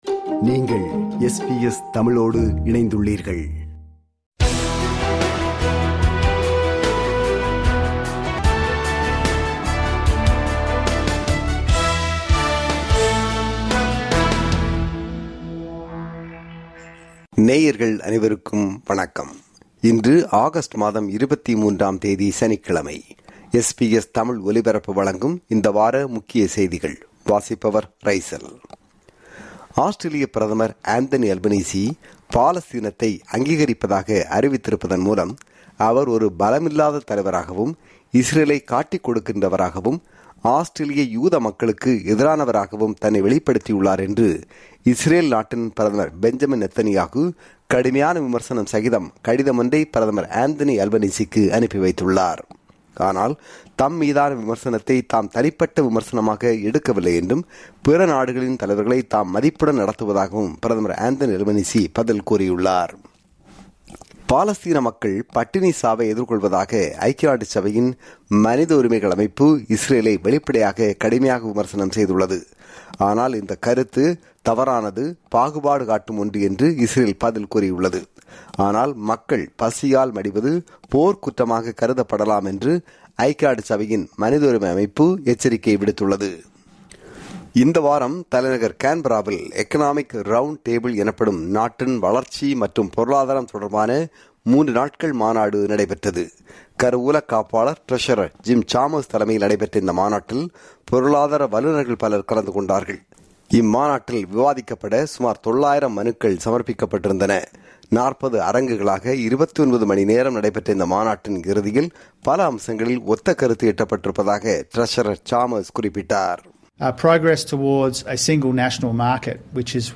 ஆஸ்திரேலிய, உலக செய்திகளின் இந்த வார தொகுப்பு